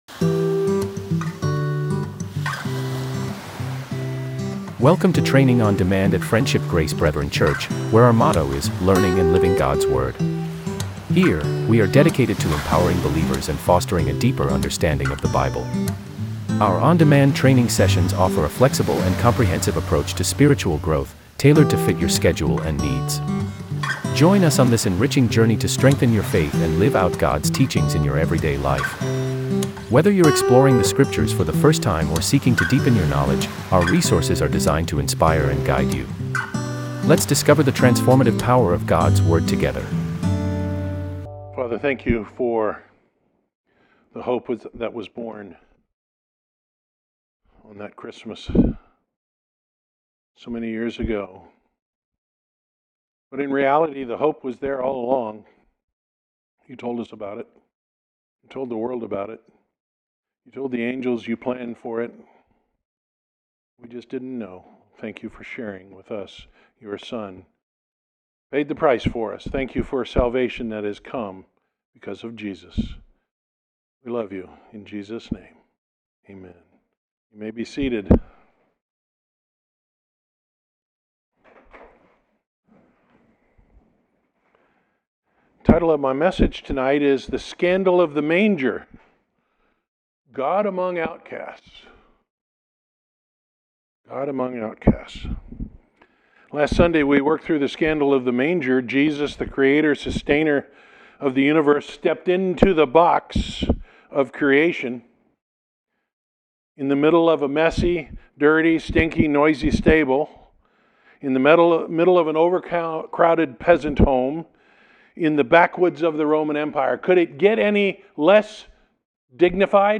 Topic: Christmas Eve Candlelight